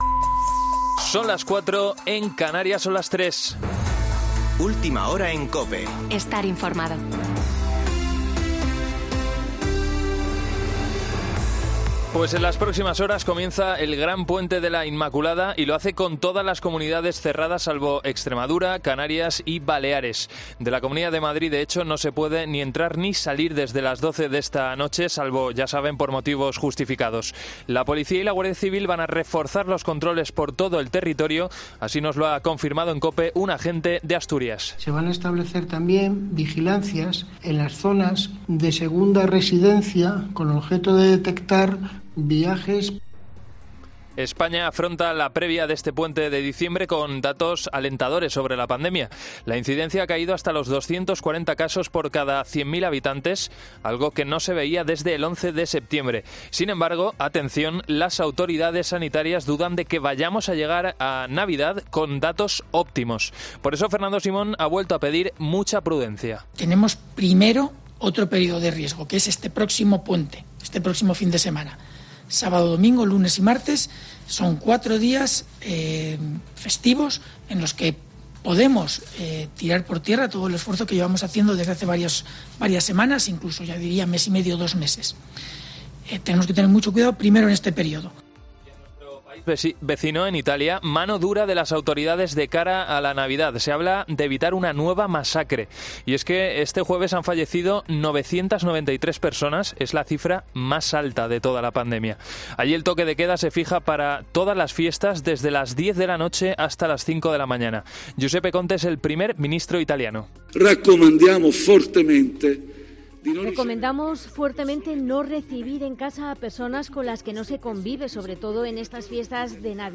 Boletín de noticias COPE del 04 de diciembre de 2020 a las 04.00 horas